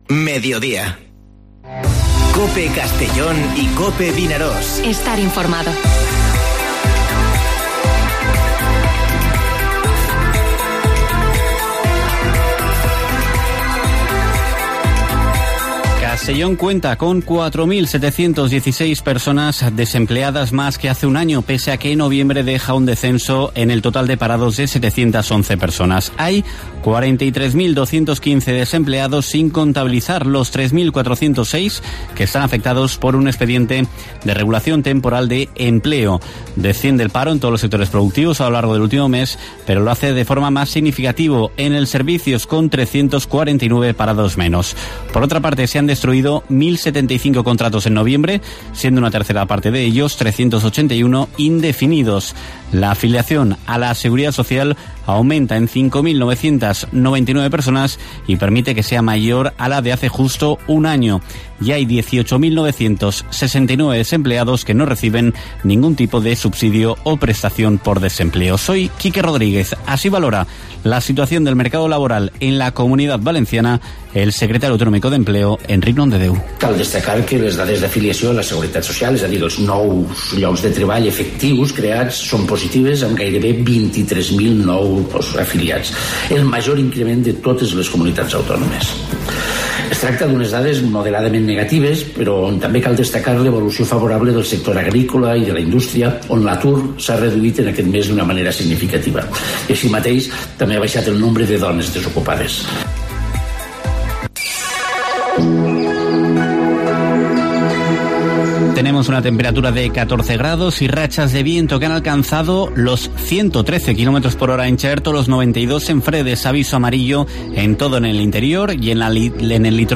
Informativo Mediodía COPE en la provincia de Castellón (02/12/2020)